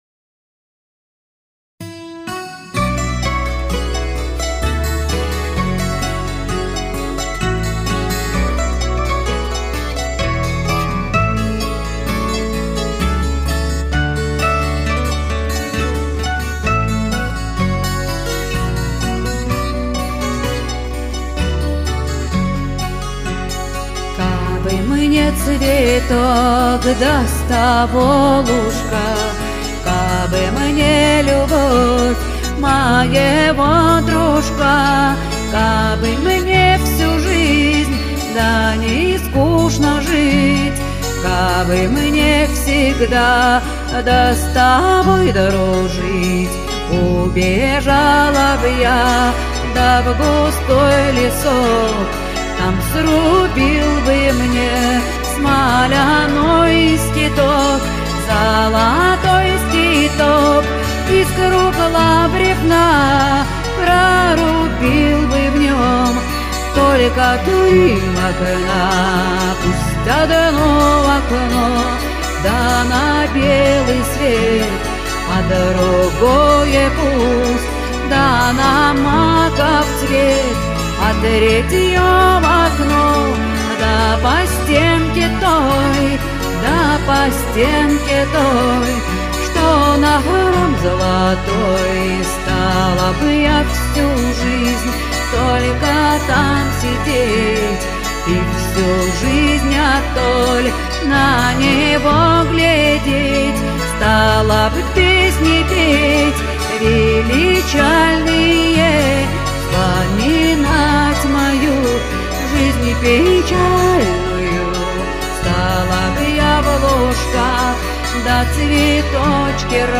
Народная музыка